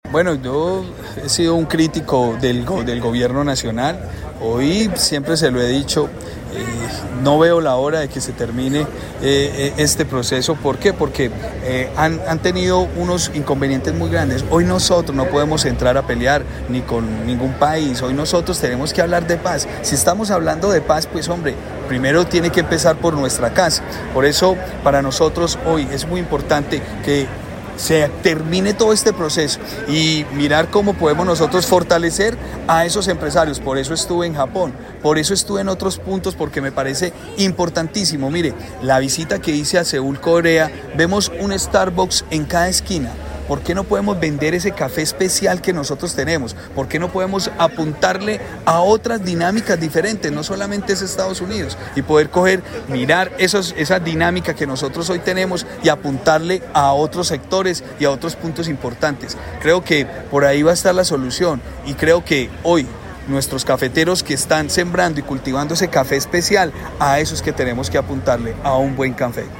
Gobernador del Quindío